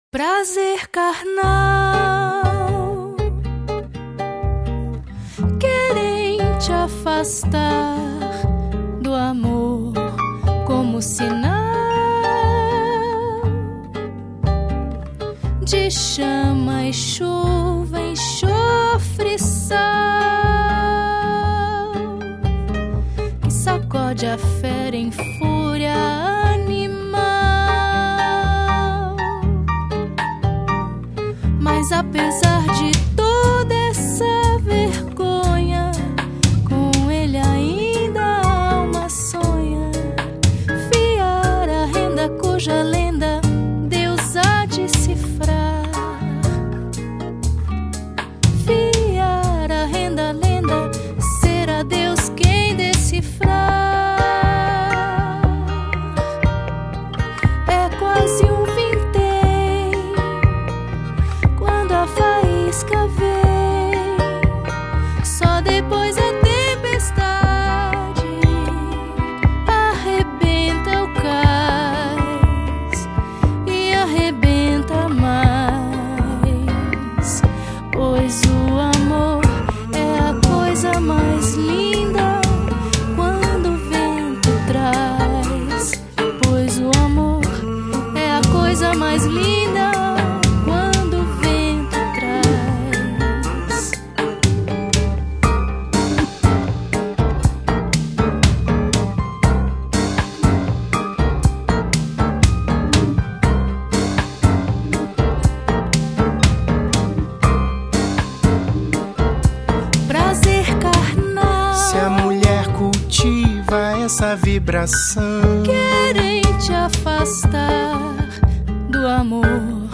Let even op de hele bijzondere pianobegeleiding.